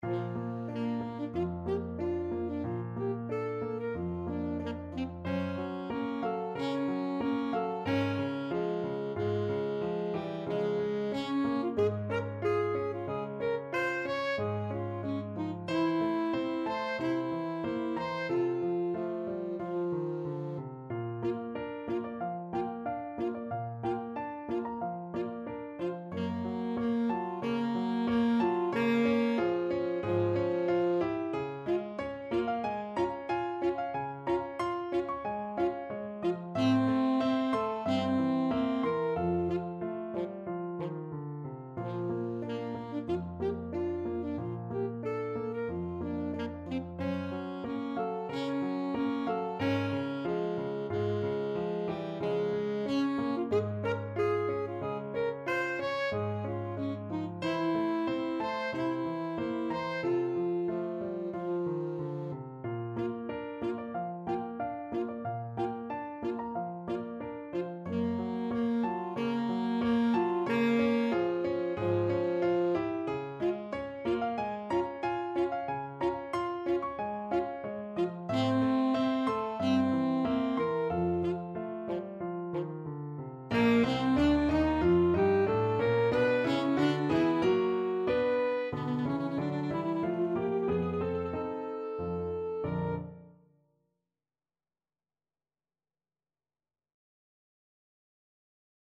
Alto Saxophone version
4/4 (View more 4/4 Music)
Andantino =92 (View more music marked Andantino)
B4-Bb5
Saxophone  (View more Intermediate Saxophone Music)
Classical (View more Classical Saxophone Music)